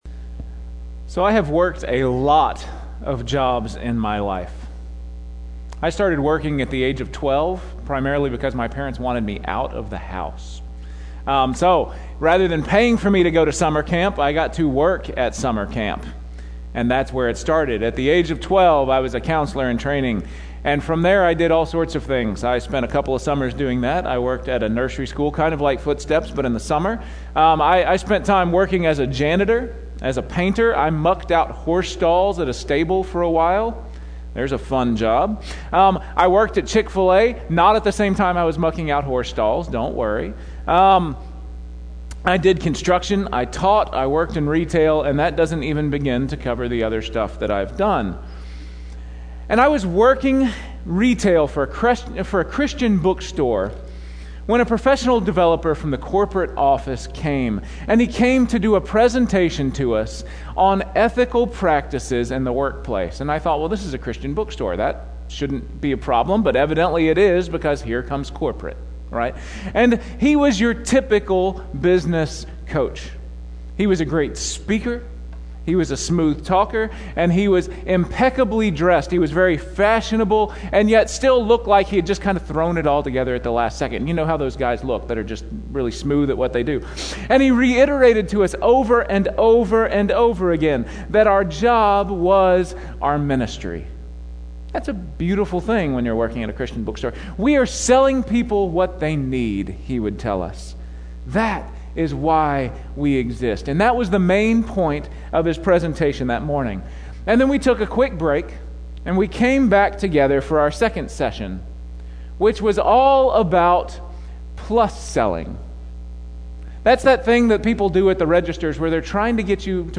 Weekly Sermon Audio “Redeeming Work”